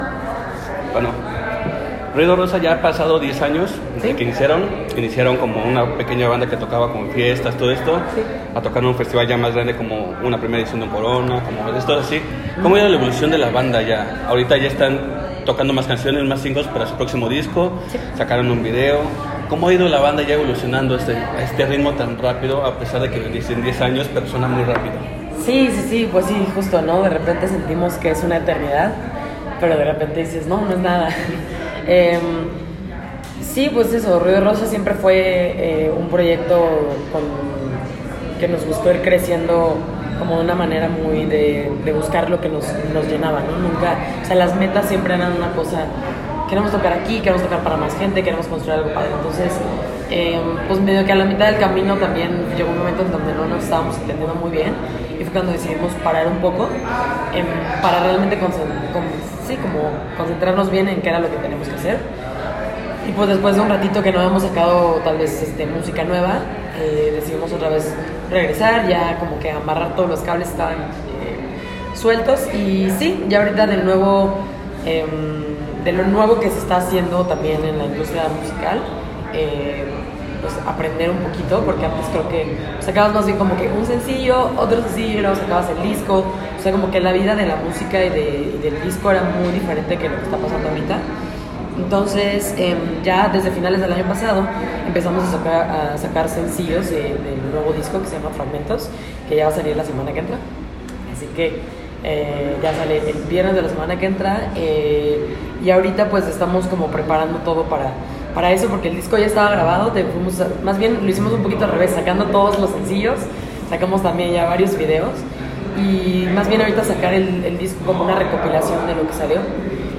en el Hotel Virreyes de la Ciudad de México